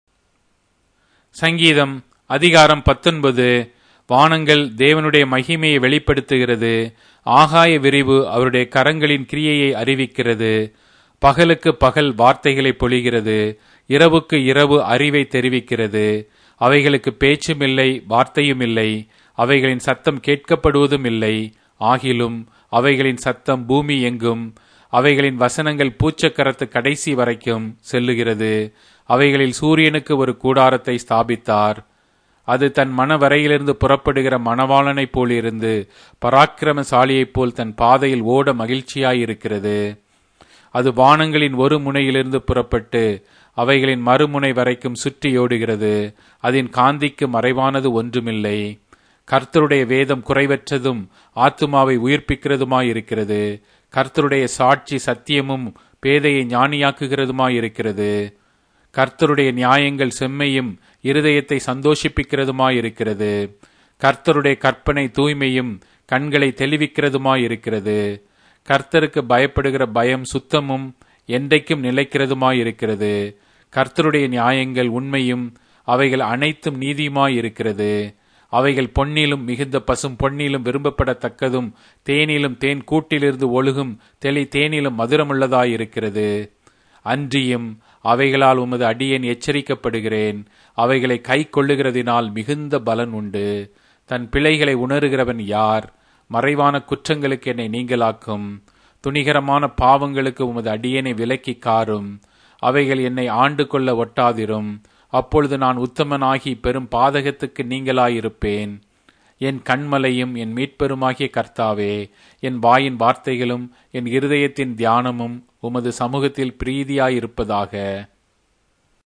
Tamil Audio Bible - Psalms 4 in Gntbrp bible version